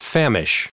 1518_famish.ogg